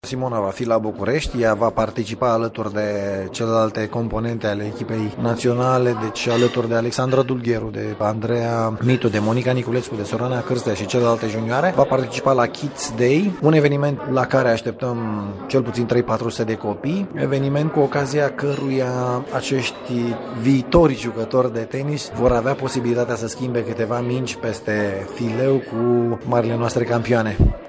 Iată ce a declarat la conferinţa de presă de azi de la sediul BRD